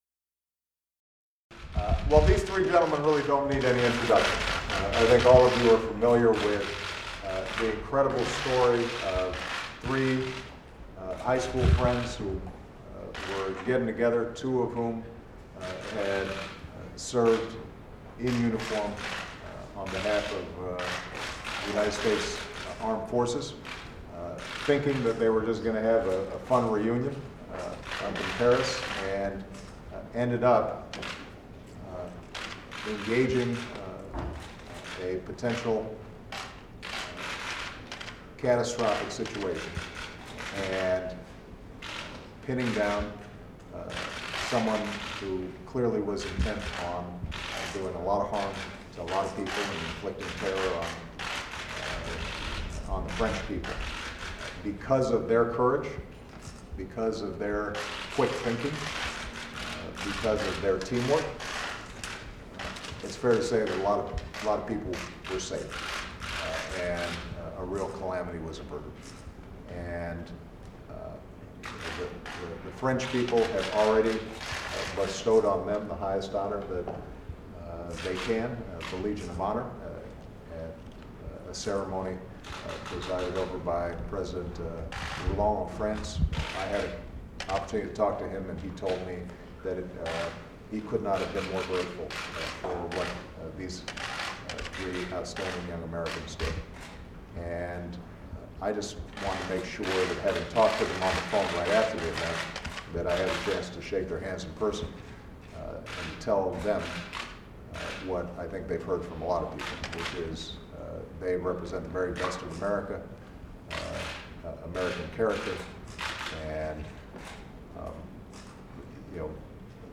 U.S. President Barack Obama delivers remarks after meeting with Spencer Stone, Alex Skarlatos, and Anthony Sadler, the three Americans who helped subdue a gunman on a packed French train in August